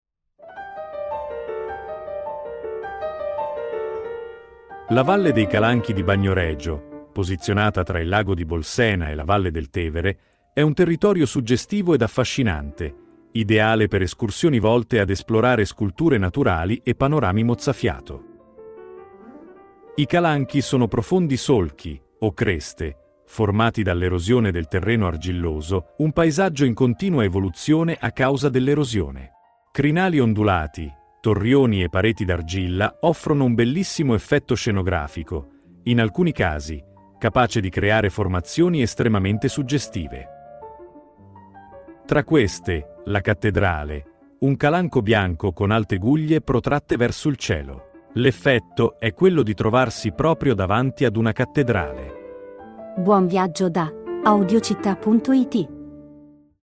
Audioguida Civita di Bagnoregio – La Valle dei Calanchi